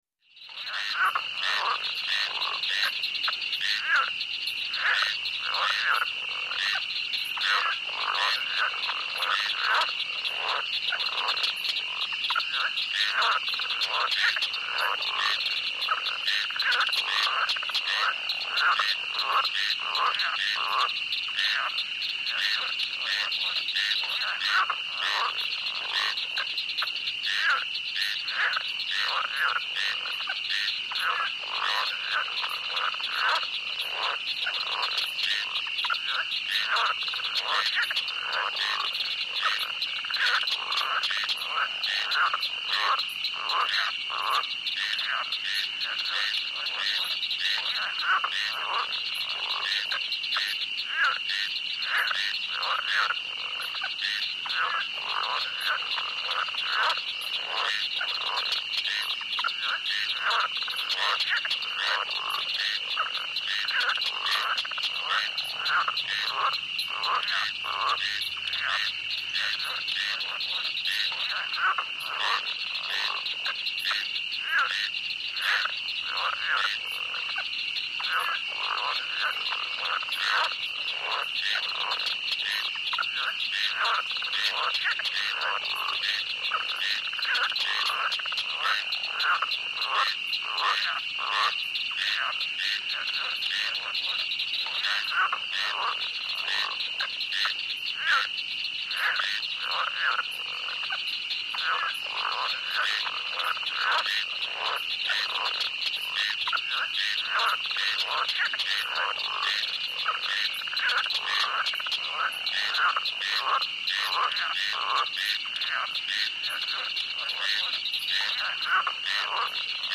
Swamp Ambience 3 [special Mix],; Light Chorus Of Frogs Croak, Close Perspective. Crickets And Insects Chirp, Distant Perspective; Calm.